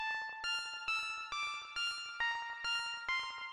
合成器旋律陷阱
描述：合成器旋律陷阱136BPM